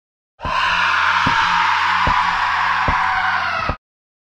На этой странице собраны звуки момо — необычные и тревожные аудиоэффекты, которые подойдут для творческих проектов.